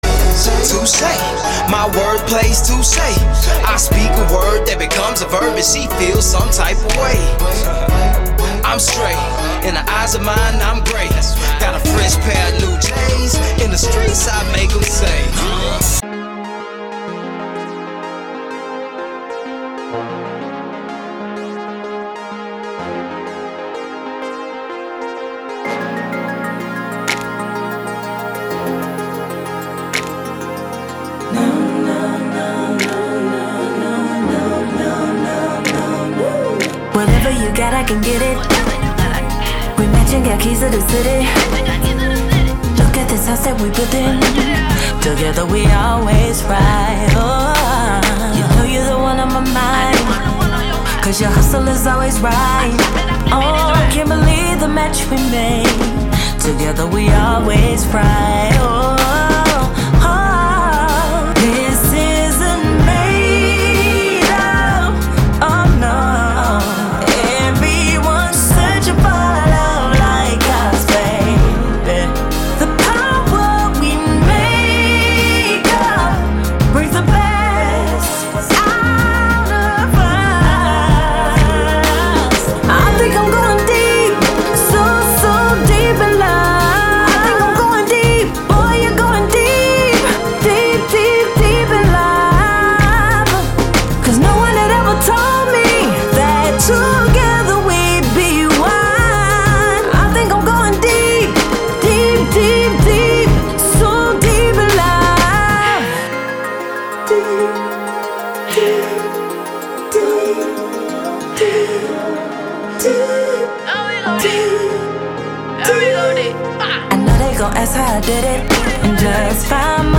Old School RnB